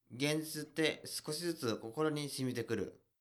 ボイス
男性
dansei_genzituttesukoshizutukokoronishimitekuru.mp3